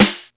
SNR XC.SNR00.wav